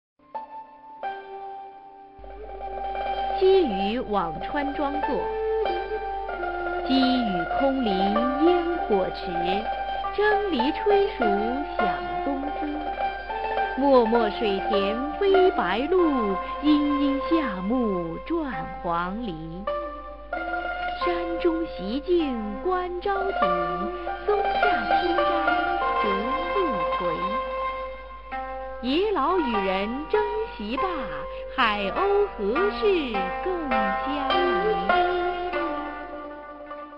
[隋唐诗词诵读]王维-积雨辋川庄作a 配乐诗朗诵